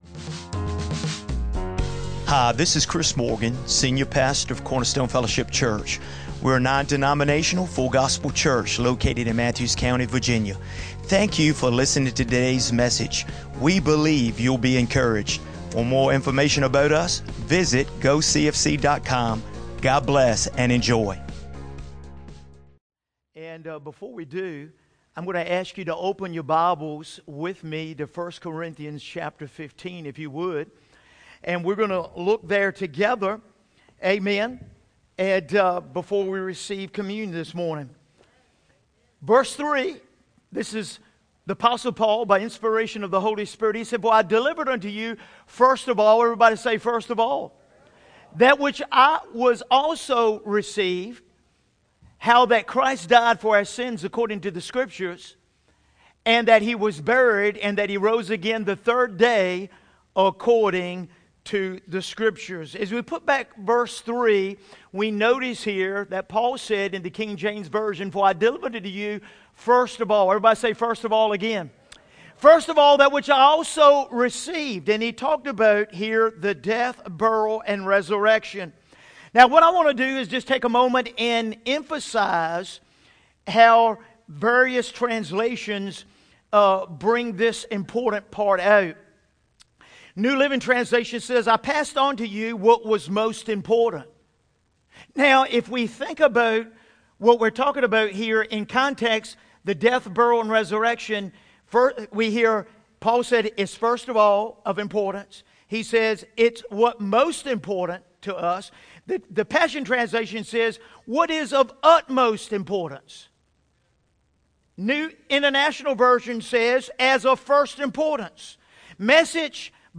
2024 Sunday Morning In this impactful message